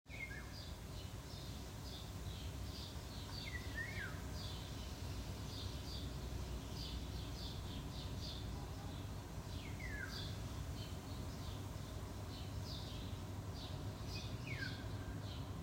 иволга, Oriolus oriolus
Administratīvā teritorijaRīga
СтатусПоёт